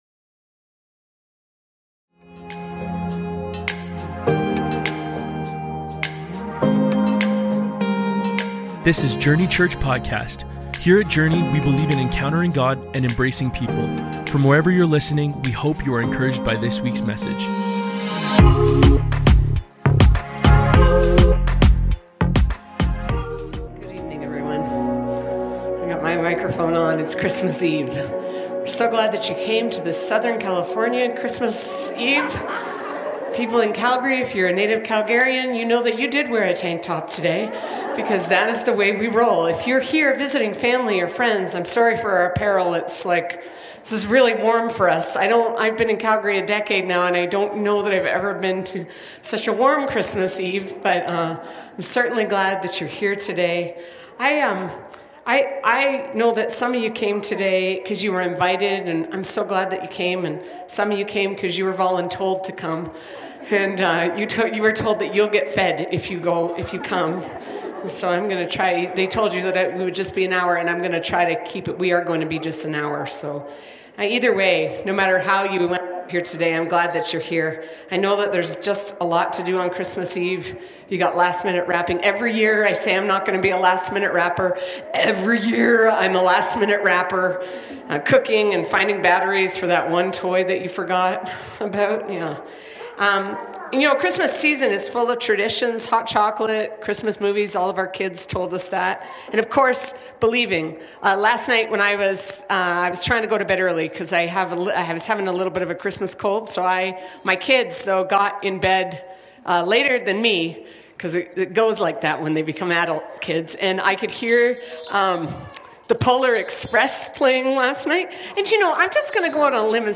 Believe | Christmas Eve Service 2025